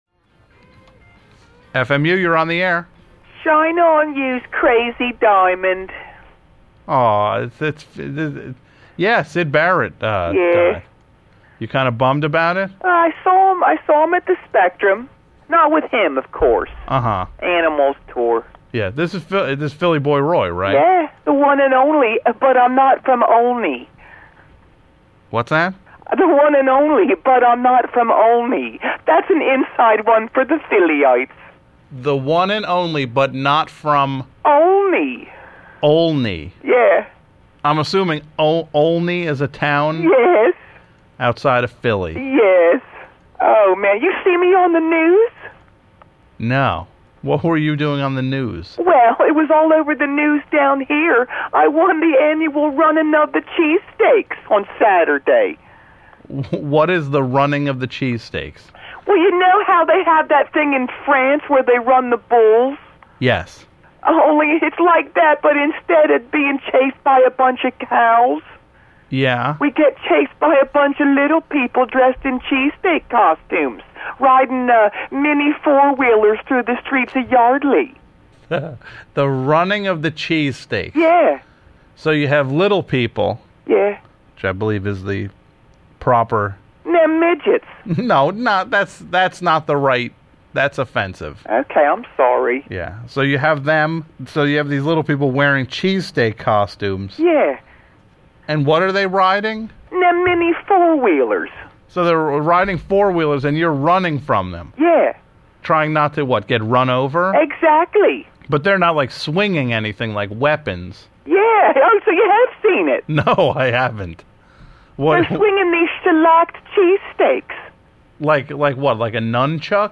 3) PHILLY BOY ROY - Philadelphia's favorite hoagie maker calls with news of his psychic son... and something called 'Laser Allin'.